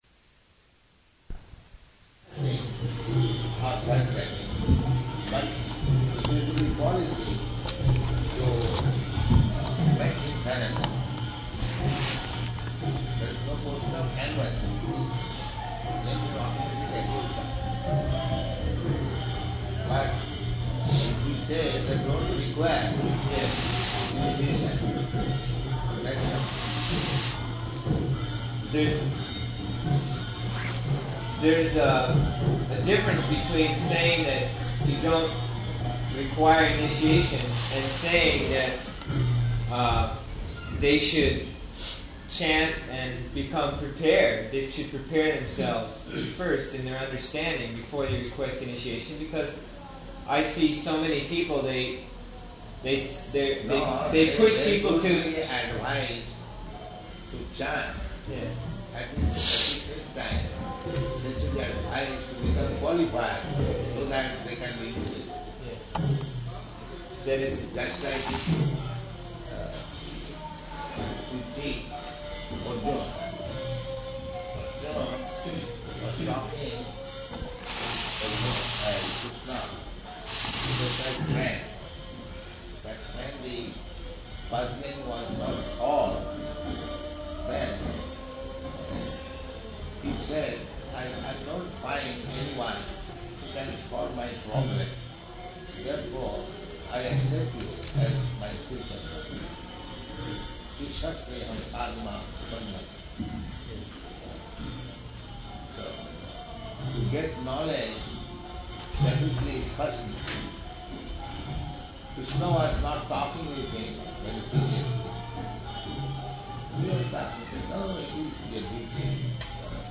Room Conversation
Room Conversation --:-- --:-- Type: Conversation Dated: June 15th 1975 Location: Honolulu Audio file: 750615R4.HON.mp3 Prabhupāda: [indistinct--- kīrtana in background throughout] ...policy to make him surrender.